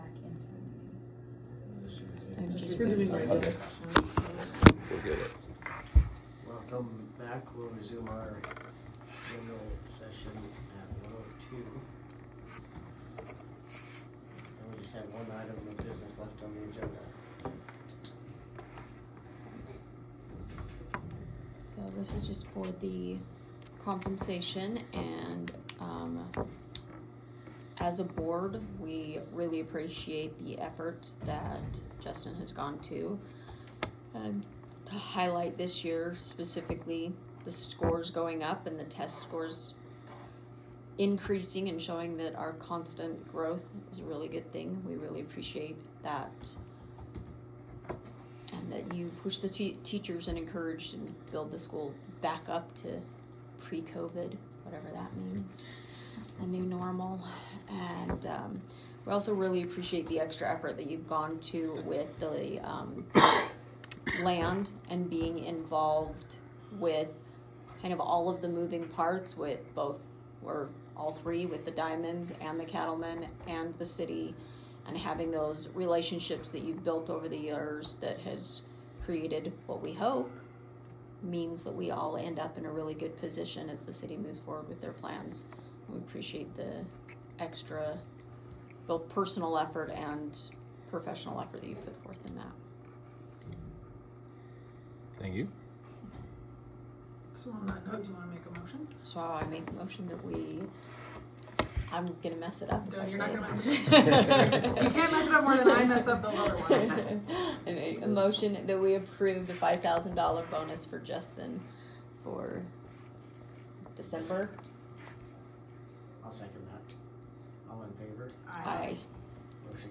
Public Board Meeting